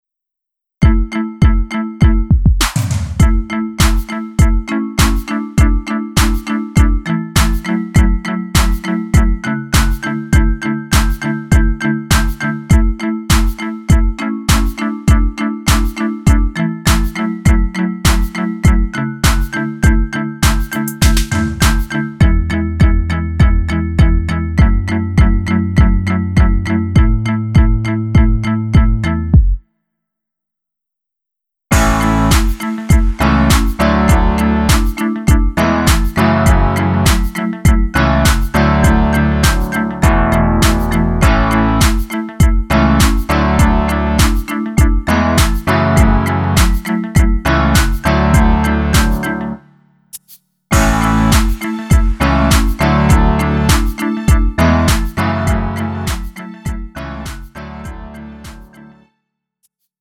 음정 원키 3:07
장르 가요 구분